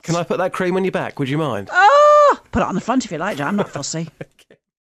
Kim Woodburn (from 'How Clean is Your House') gives us her Spring Clean tips...and more than a little innuendo...Hear the whole interview after 7am today, on Saturday Breakfast!!